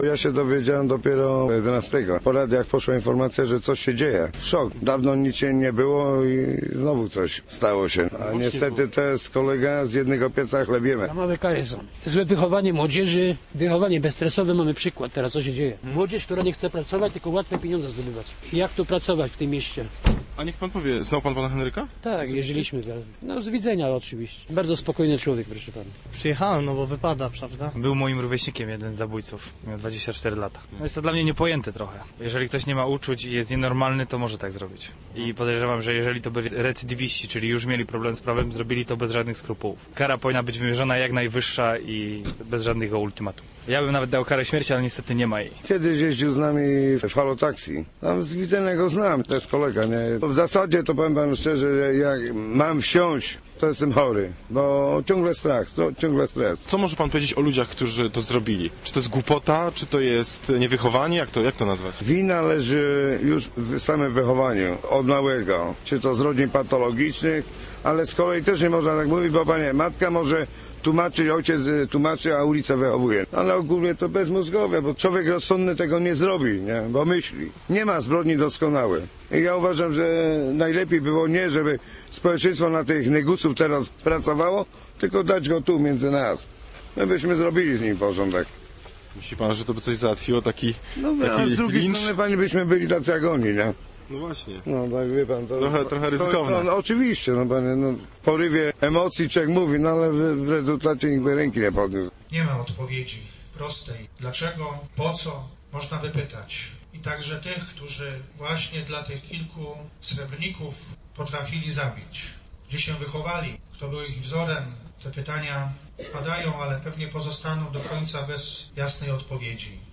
Pogrzeb zamordowanego taksówkarza